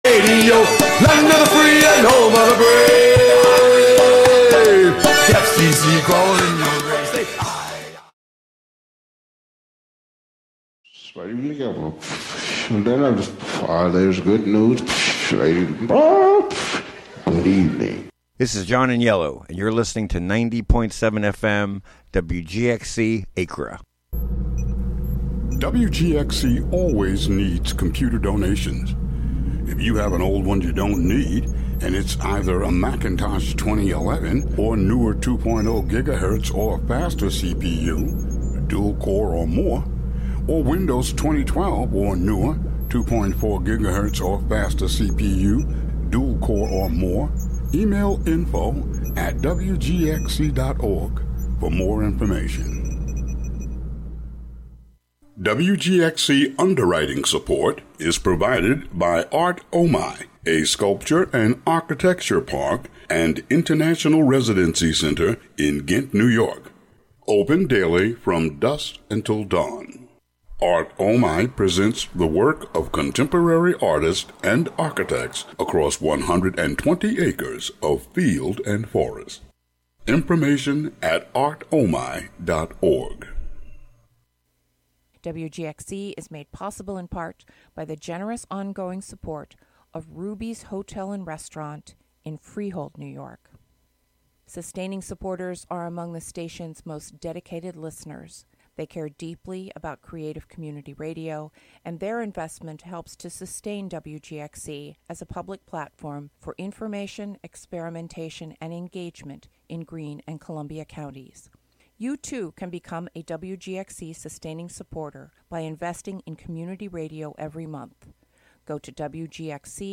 Broadcast live from HiLo in Catskill.